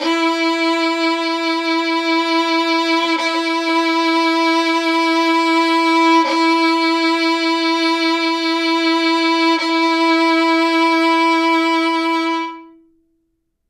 interactive-fretboard / samples / violin / E4.wav
E4.wav